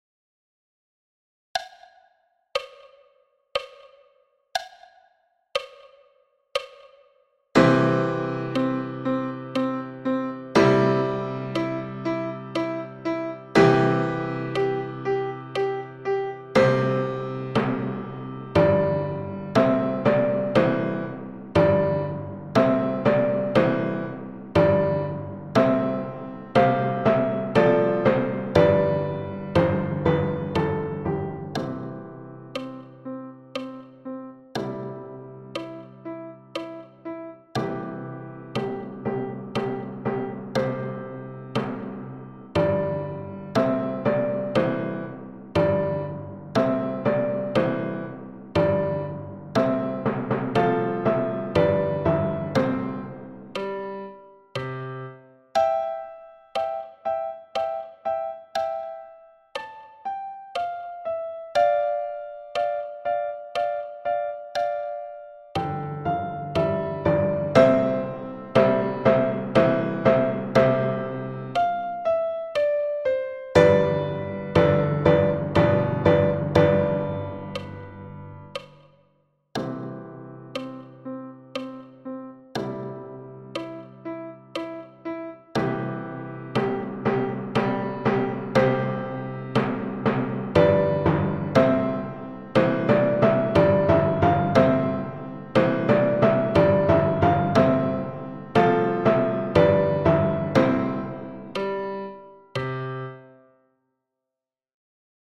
Fanfare VO – tutti à 60 bpm
Fanfare-VO-tutti-a-60-bpm.mp3